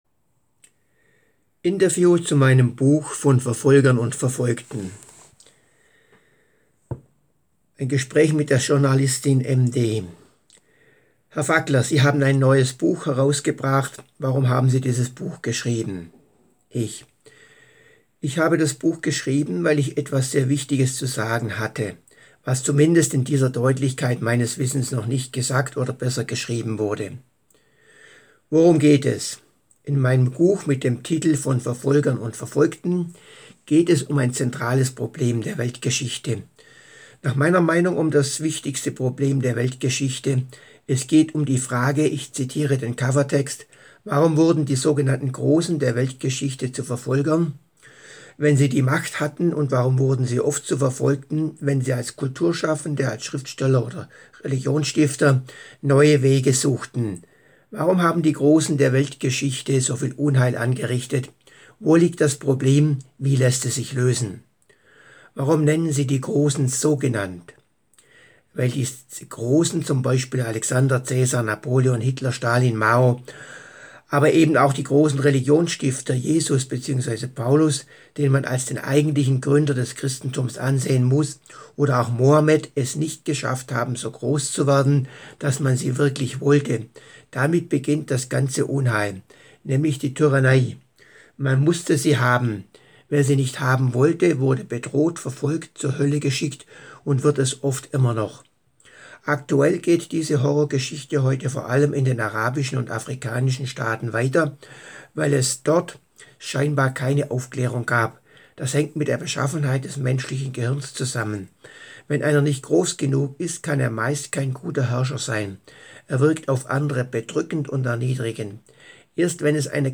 Interview
Interview zu meinem neuen Buch: